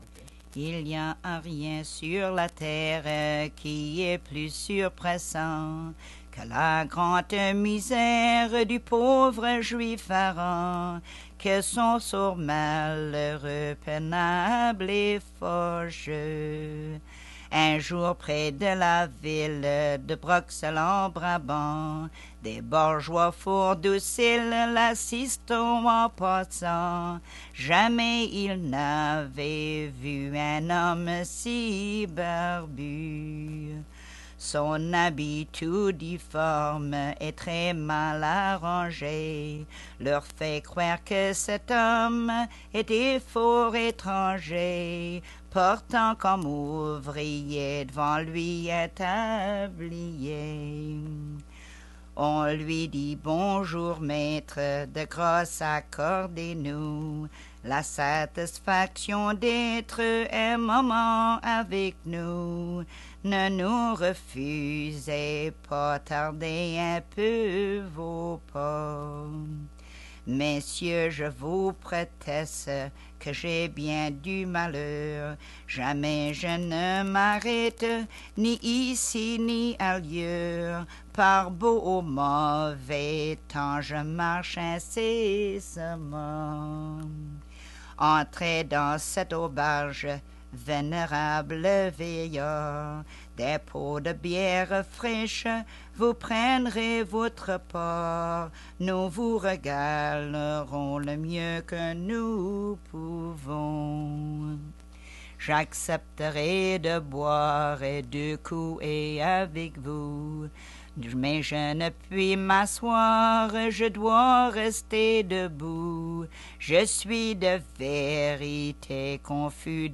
Emplacement L'Anse-aux-Canards